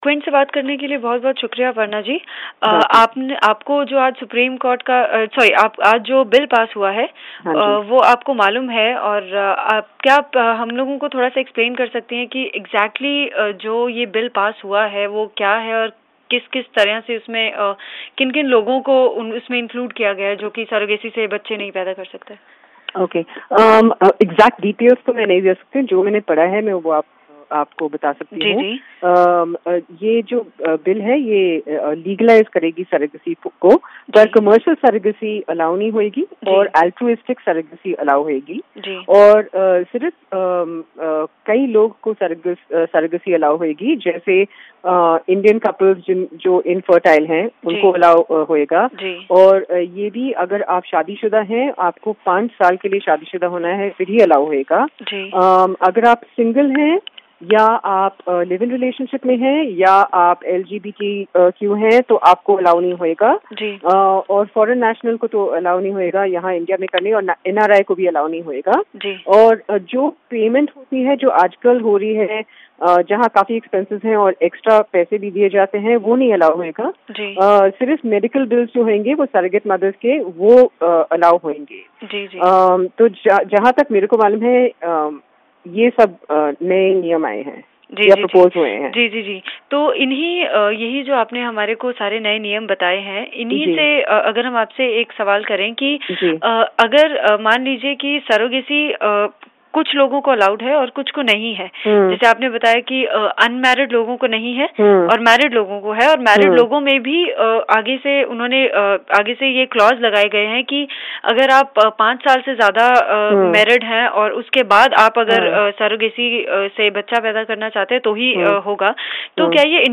सरोगेसी पर खास बातचीत